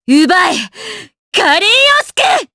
Xerah-Vox_Skill1_Madness_jp.wav